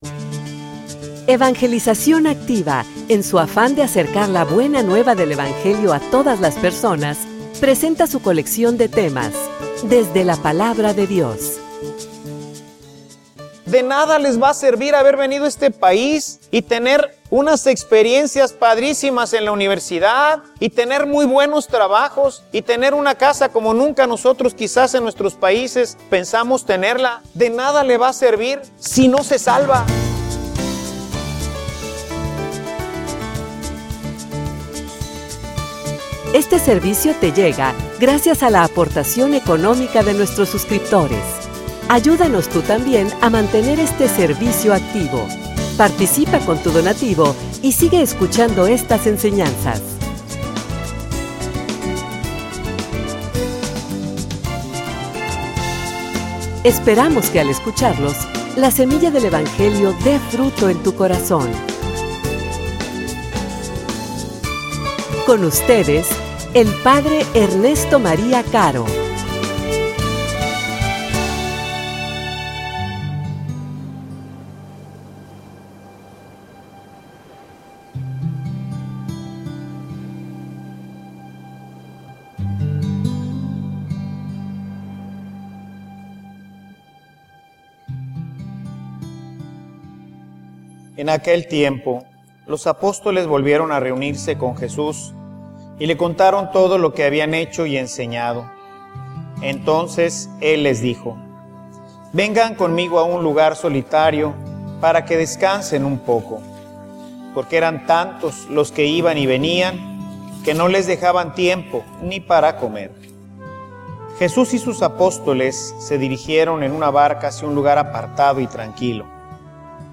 homilia_Cuida_tus_ovejas.mp3